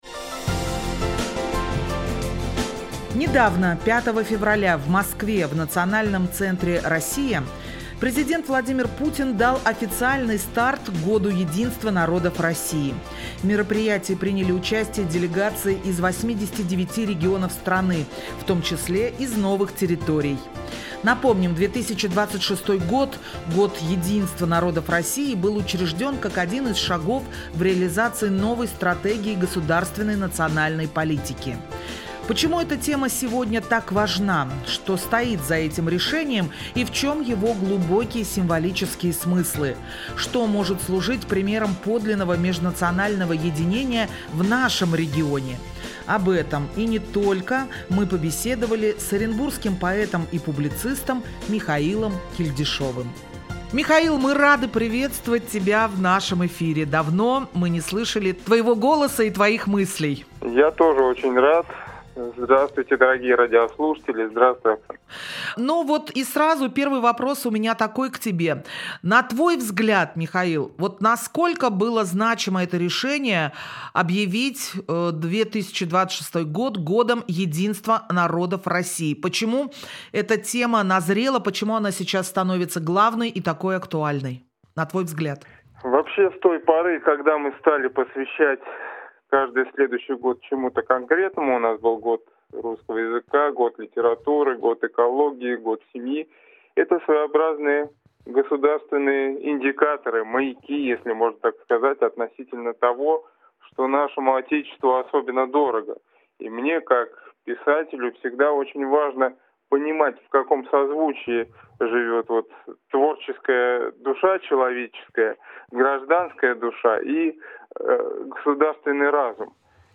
Беседу вела